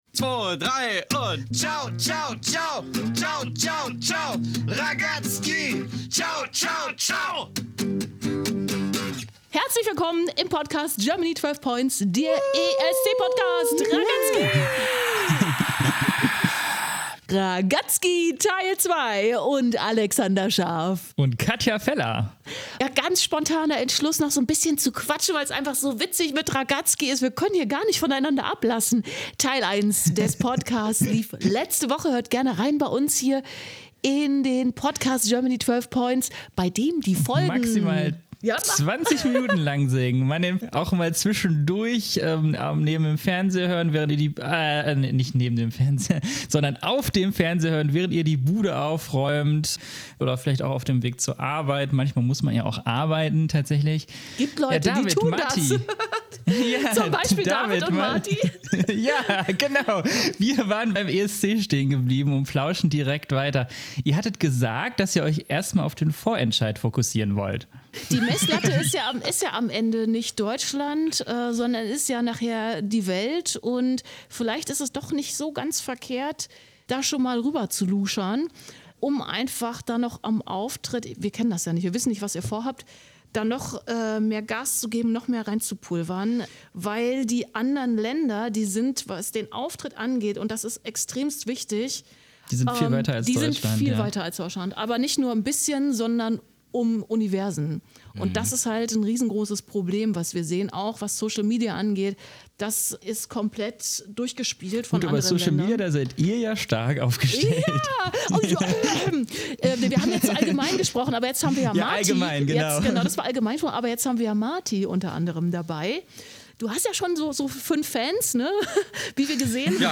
Ragazzki INTERVIEW